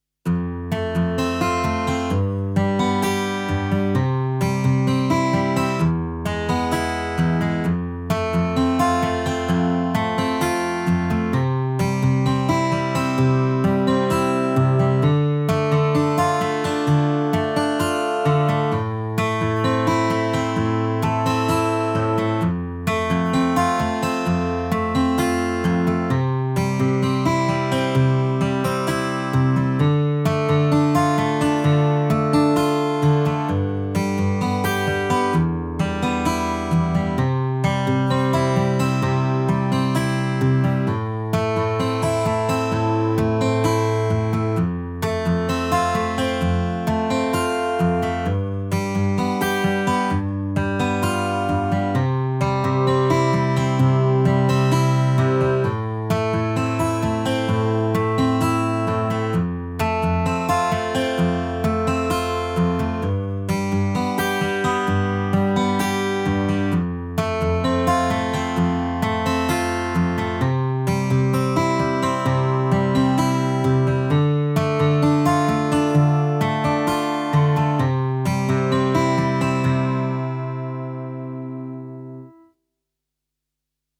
Ich glaube, mit den Tiefen und Mitten bin ich einigermaßen zufrieden, jedoch bekomme ich das übertrieben Silbrige einfach nicht rausgemischt, denn welche Frequenz ich auch angehe, die heraussticht, es scheint sich um mehrere Bereiche zu handeln.
Der erste Dateianhang ist mein Ergebnis im Mix (ohne Gesang), der zweite Anhang die trockene Audiospur. Anhänge Gitarre Mix.mp3 2,5 MB Wave Gitarrenspur.zip 12,1 MB · Aufrufe: 118